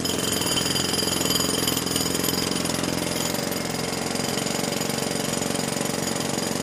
Jackhammer Hammering Concrete Loop